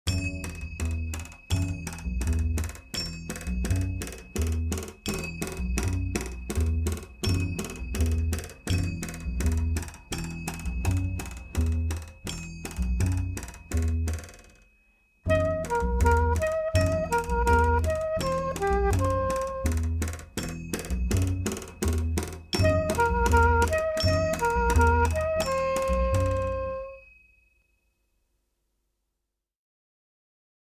alto, soprano, C-merlody
double bass, voice
drums